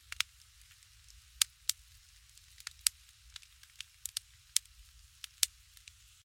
Torch sfx added.
torch.ogg